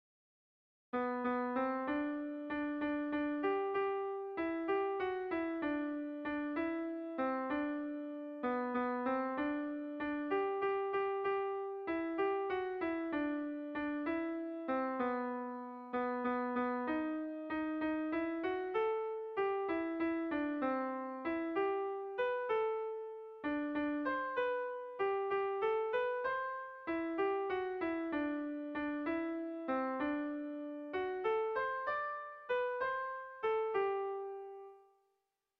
Kontakizunezkoa
Zortziko handia (hg) / Lau puntuko handia (ip)
A1A2BD